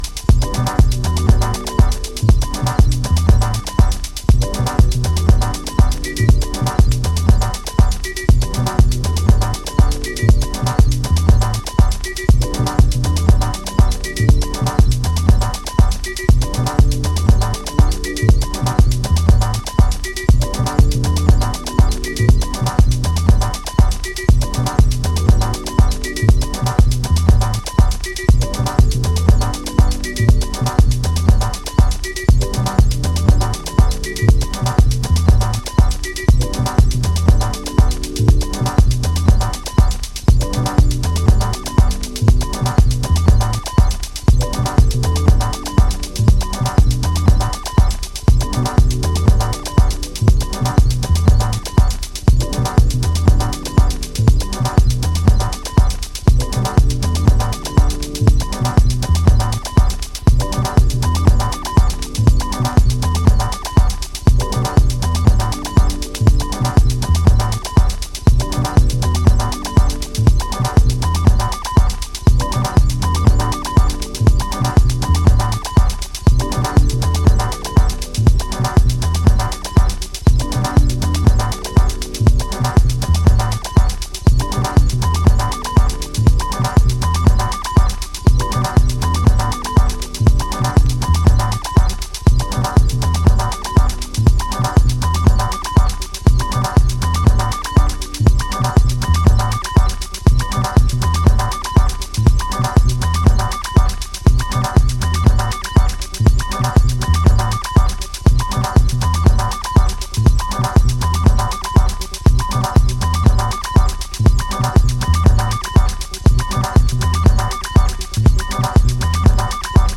クールでフューチャリスティックな北欧ハウス最前線！